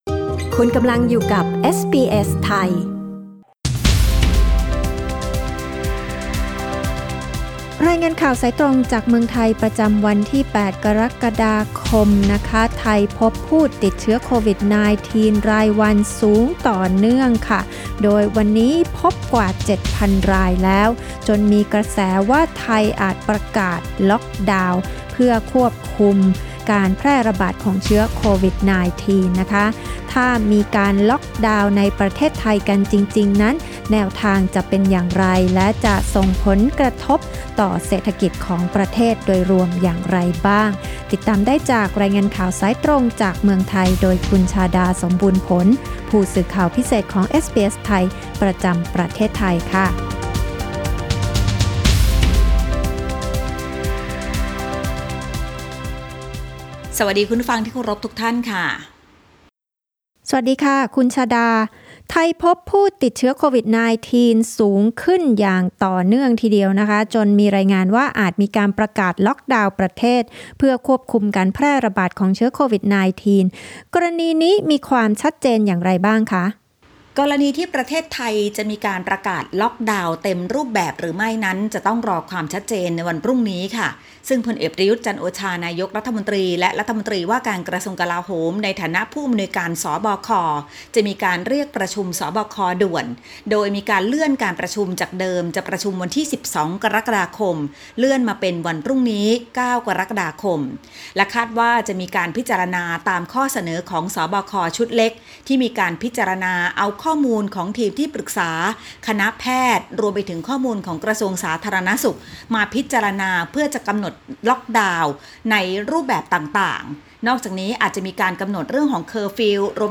รายงานข่าวสายตรงจากเมืองไทย จากเอสบีเอส ไทย Source: Pixabay